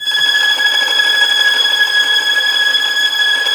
Index of /90_sSampleCDs/Roland LCDP13 String Sections/STR_Violins Trem/STR_Vls Trem wh%